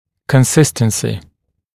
[kən’sɪstənsɪ][кэн’систэнси]постоянство, устойчивость, стабильность, консистенция